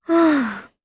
Artemia-Vox-Deny_jp_b.wav